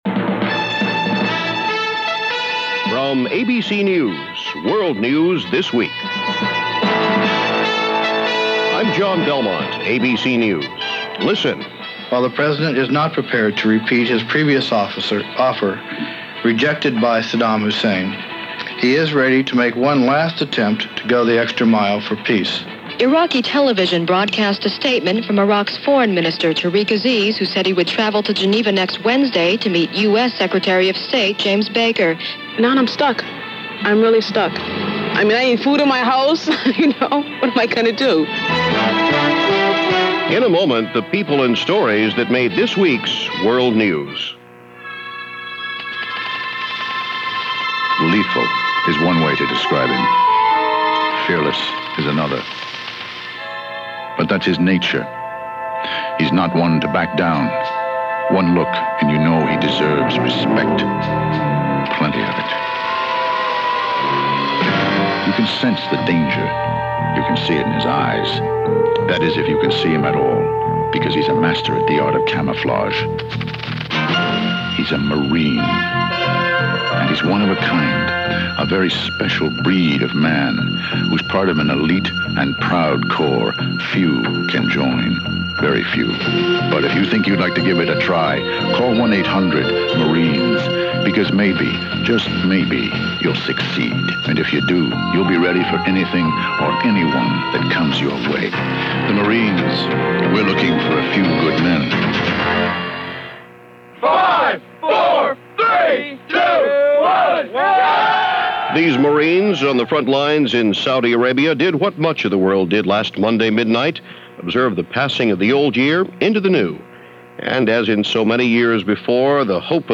Desert Storm: A Week Of Rattled Sabers - A Season Of Empty Words - January 6, 1991 - news for this week from ABC Radio.